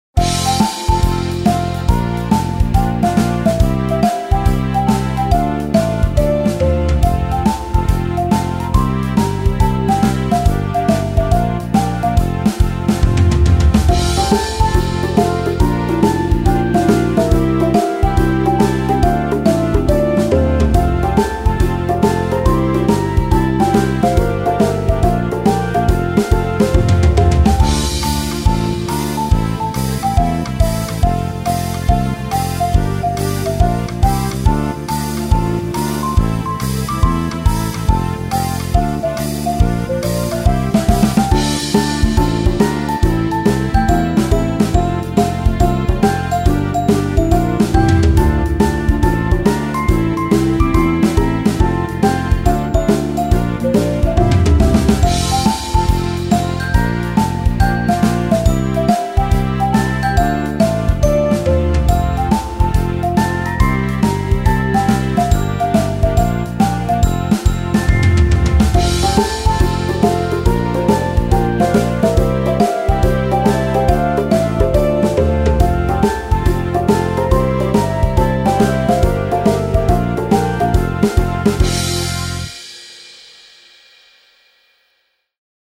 わくわくお出かけするような、日常BGMです。
わくわく かわいい 春 夏 前向き 明るい 爽やか 笑顔